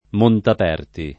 montap$rti] o Monteaperti [